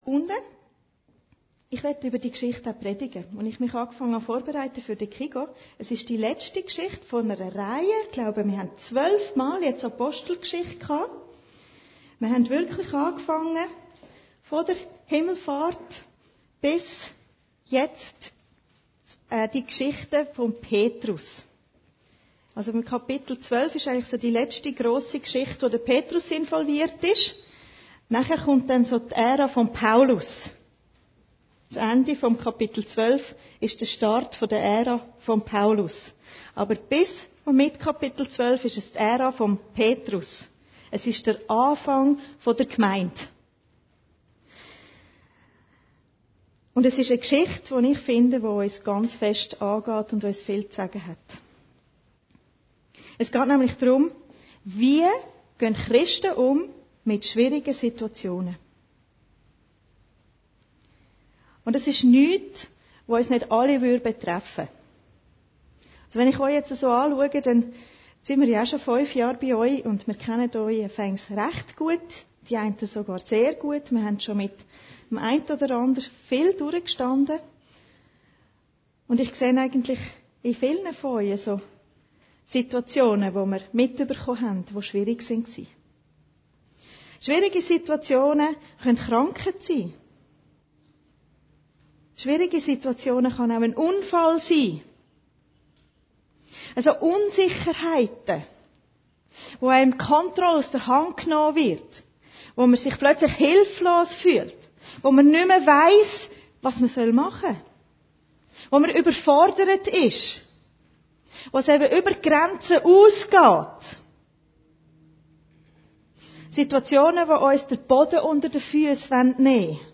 Predigten Heilsarmee Aargau Süd – Petrus im Gefängnis